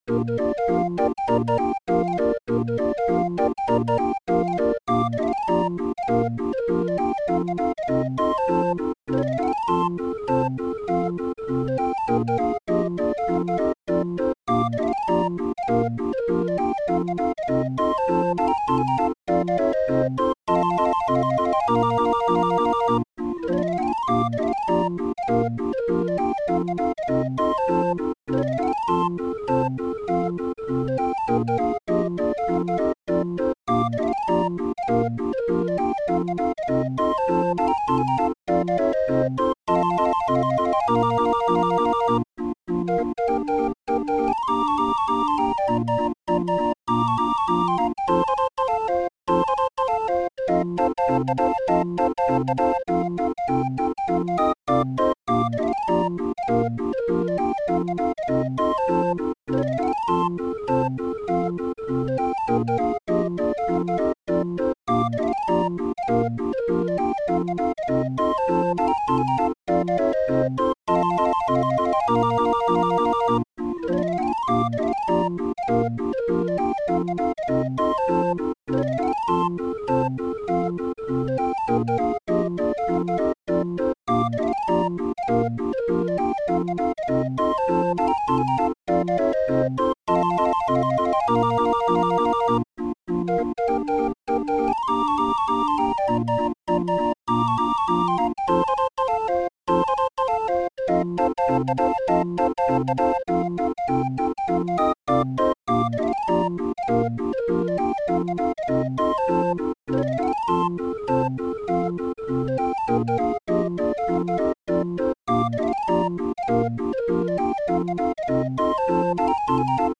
Musikrolle 31-er